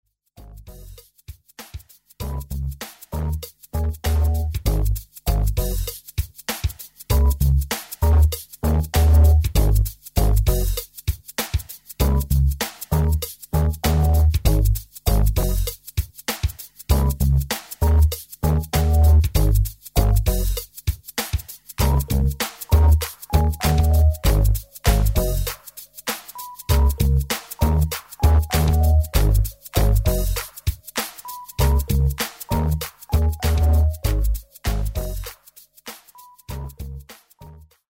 • Music Genre: Pop